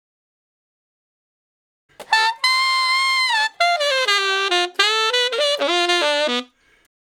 066 Ten Sax Straight (D) 28.wav